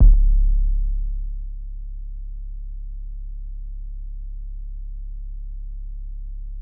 Southside 808 (24).wav